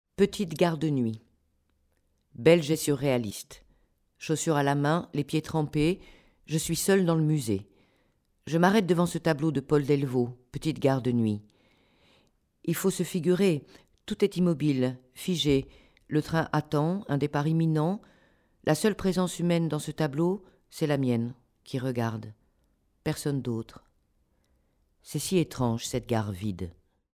grave